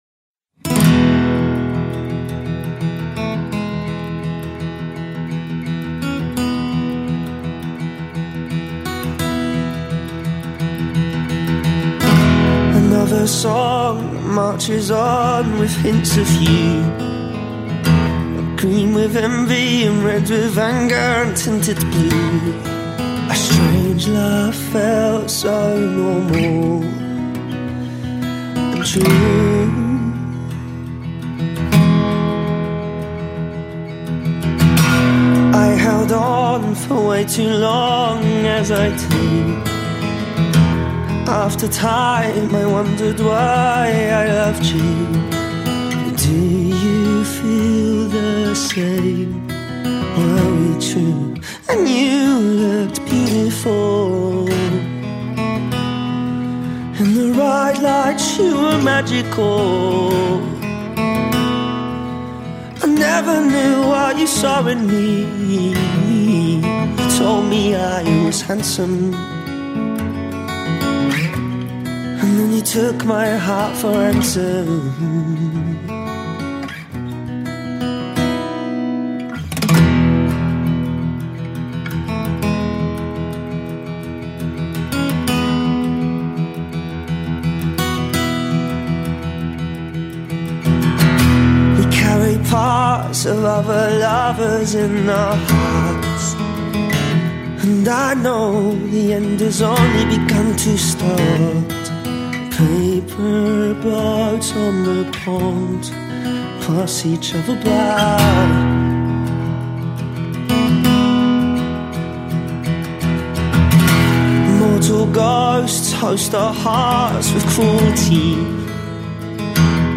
a stirring and exemplary slice of indie folk